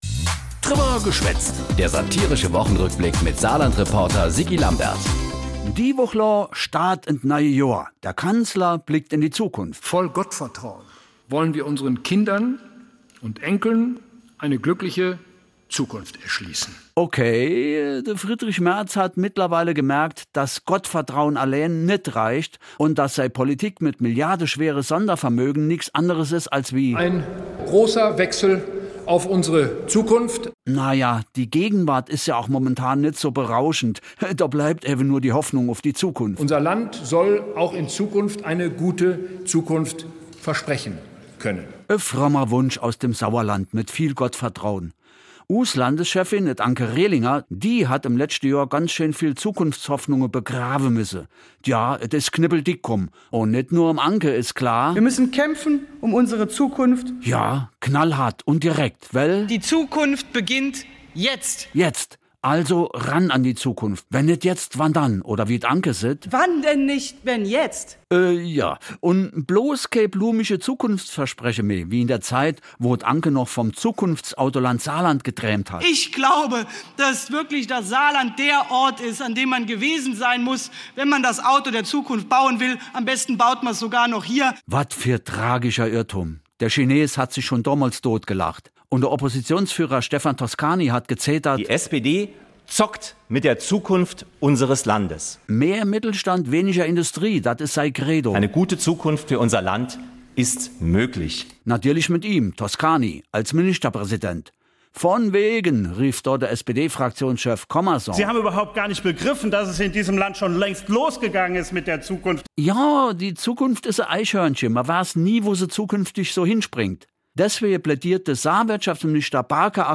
Satirischer Rückblick auf die Ereignisse der Woche jeweils samstags (in Dialekt)